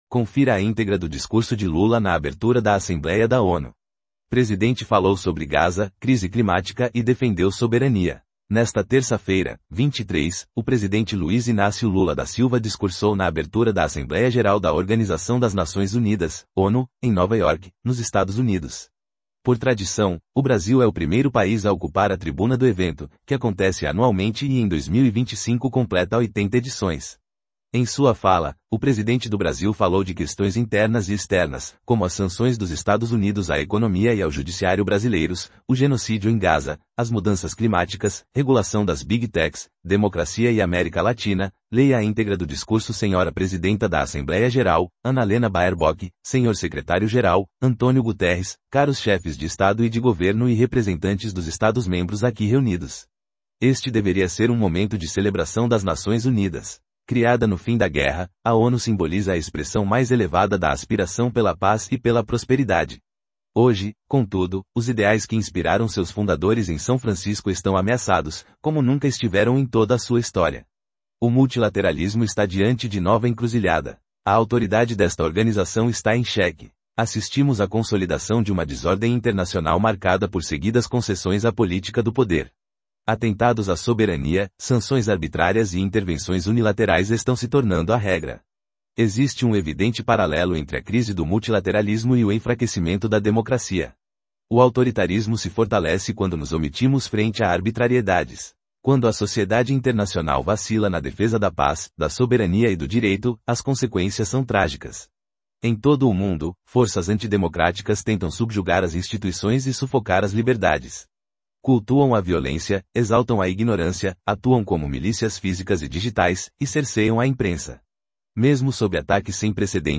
Leia a íntegra do discurso
Nesta terça-feira (23), o presidente Luiz Inácio Lula da Silva discursou na abertura da Assembleia Geral da Organização das Nações Unidas (ONU), em Nova York, nos Estados Unidos.